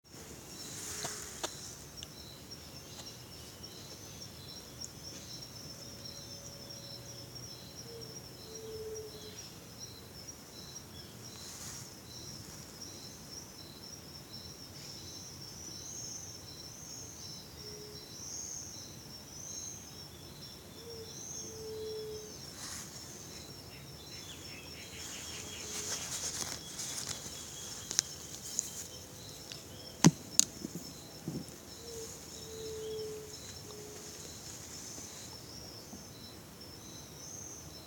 Yerutí Común (Leptotila verreauxi)
Nombre en inglés: White-tipped Dove
Fase de la vida: Adulto
Localidad o área protegida: Parque del Plata
Condición: Silvestre
Certeza: Vocalización Grabada